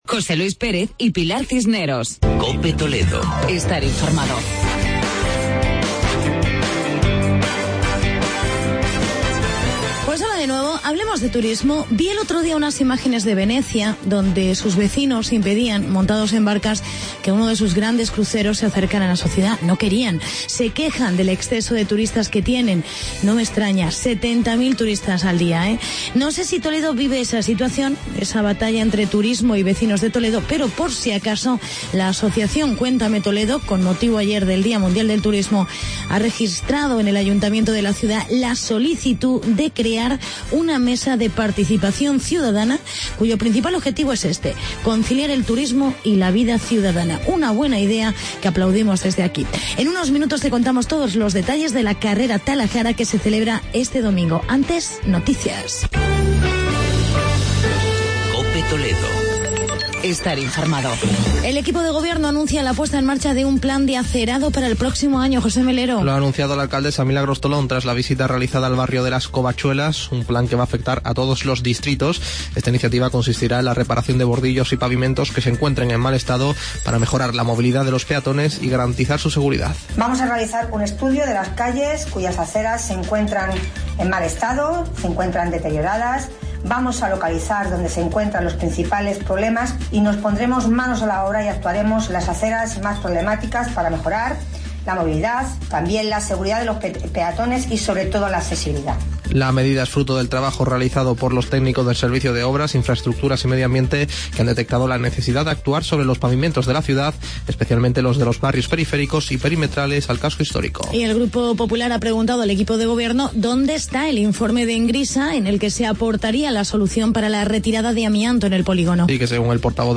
Actualidad y entrevista con el concejal de deportes Antonio Álvarez sobre "Talajara".